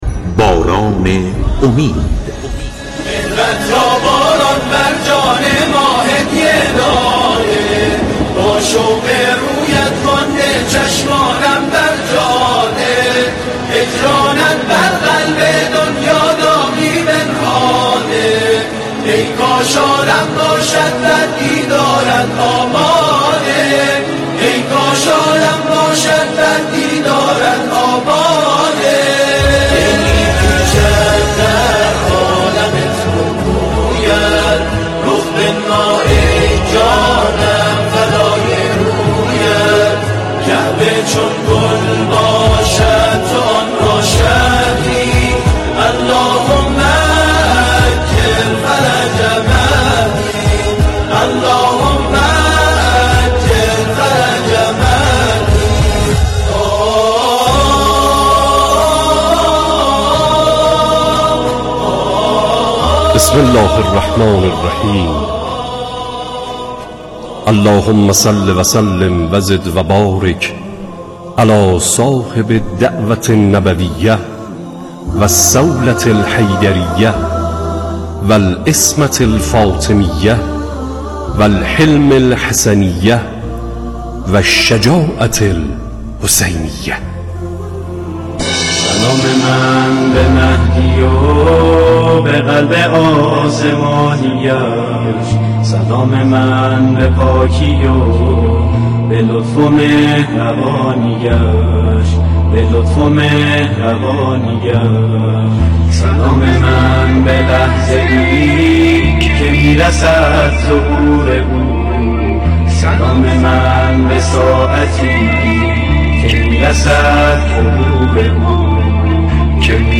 برنامه رادیویی باران امید با موضوع مهدویت از منظر قرآن کریم و آشنایی با فلسفه غیبت، جمعه‌ها به روی آنتن می‌رود.